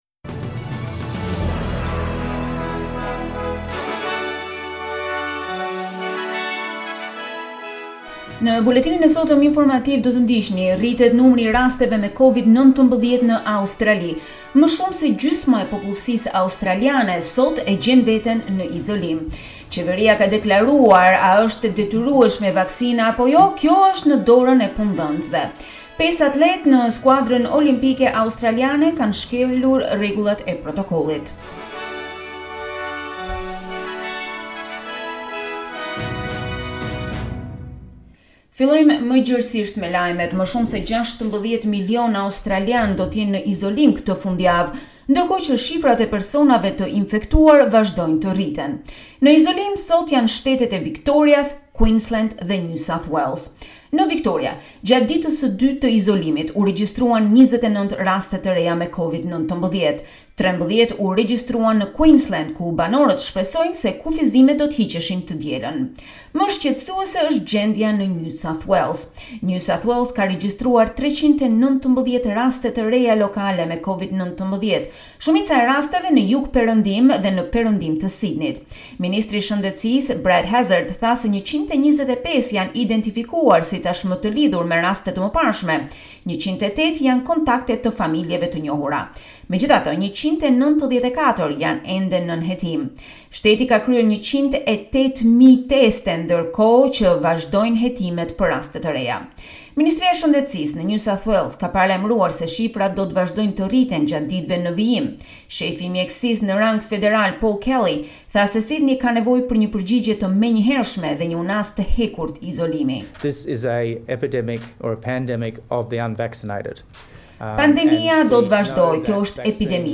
SBS News Bulletin in Albanian - 07 August 2021